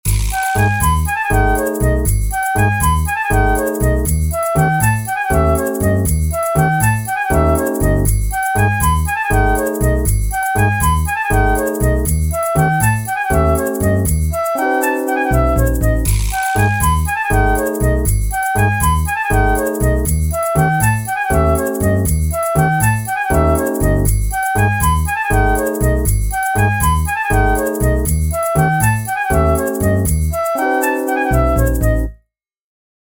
Demo of 10edo
10edo_groove.mp3